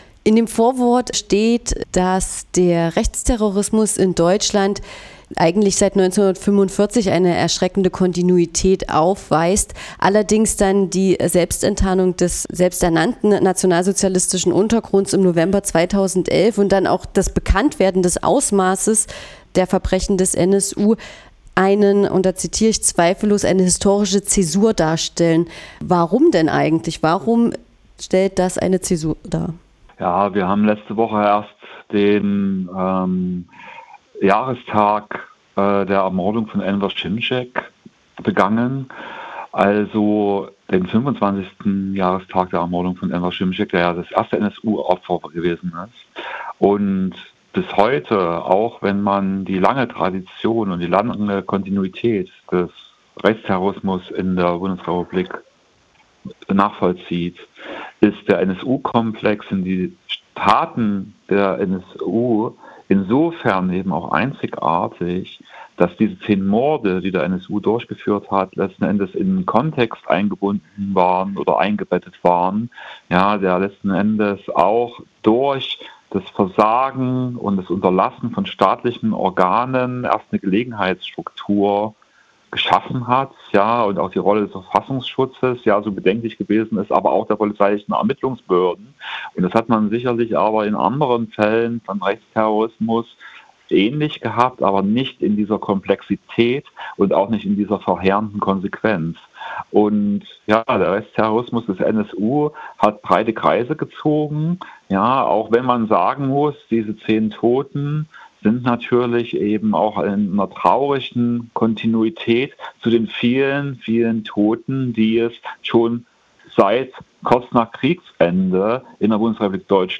25 Jahre nach dem ersten NSU-Mord: Was wurde aus den politischen Versprechen? | Interivew